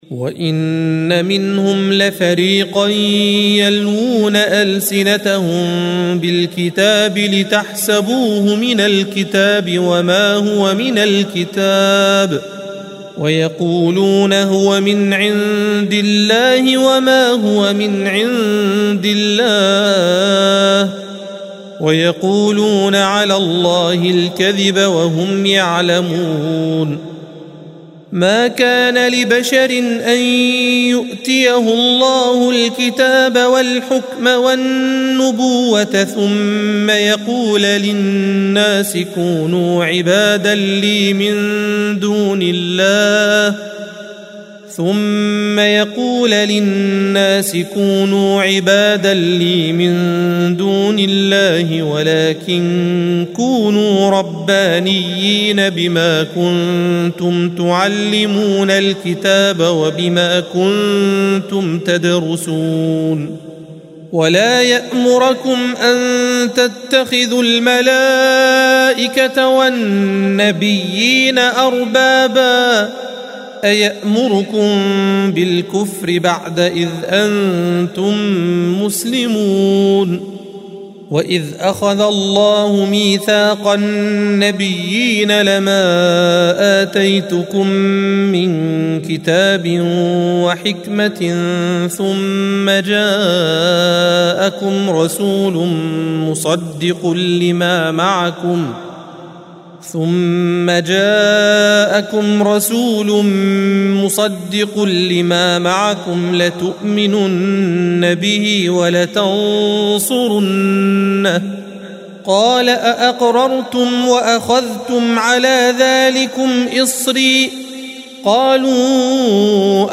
الصفحة 60 - القارئ